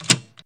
click.wav